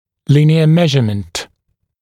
[‘lɪnɪə ‘meʒəmənt][‘линиэ ‘мэжэмэнт]линейное измерение, линейный размер